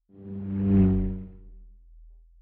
Sci-Fi Sounds / Movement / Fly By 05_1.wav
Fly By 05_1.wav